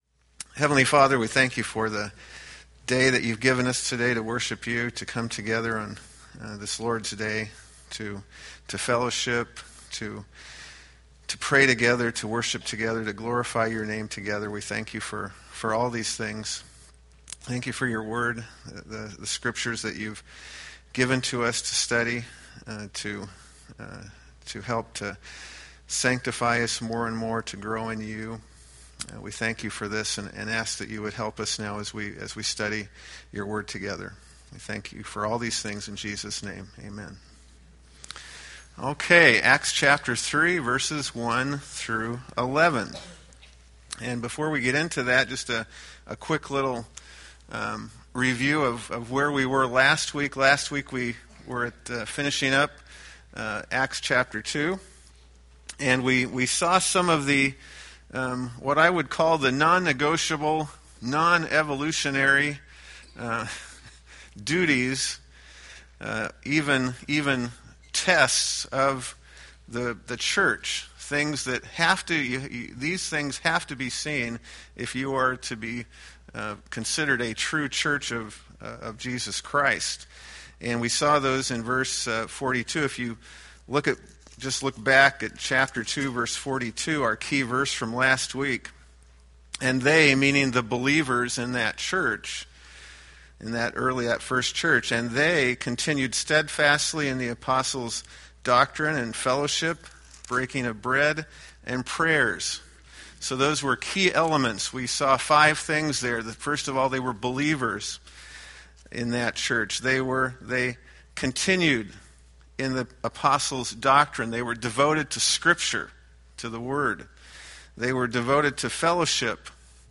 Acts Class - Week 8
Date: Jan 5, 2014 Series: Acts Grouping: Sunday School (Adult) More: Download MP3